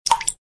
Category: Message Tones